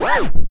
ZOWZ KICK.wav